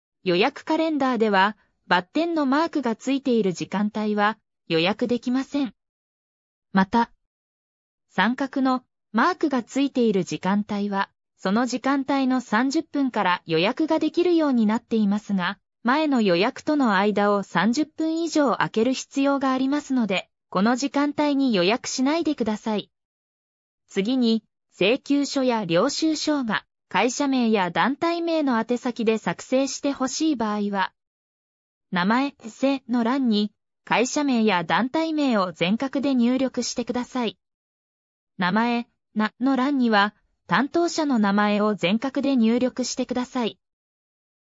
利用開始時刻と姓名欄の入力の注意（AI音声で案内）